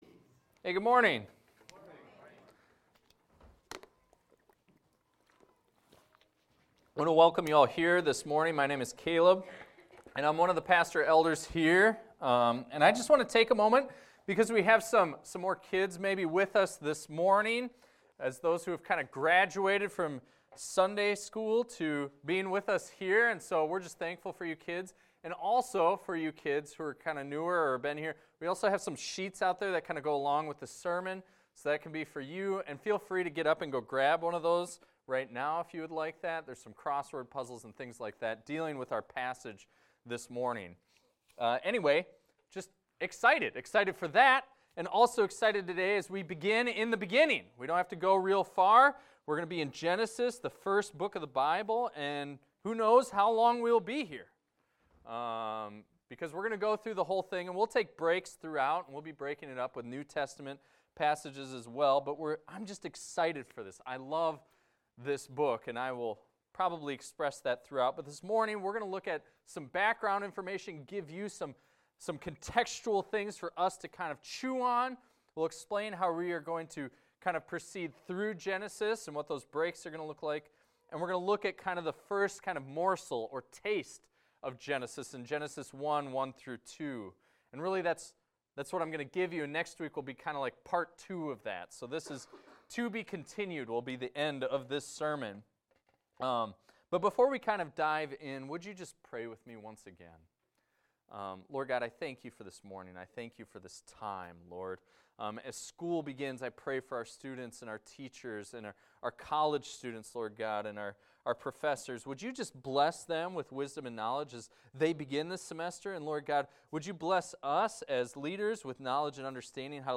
This is a recording of a sermon titled, "In the Beginning...."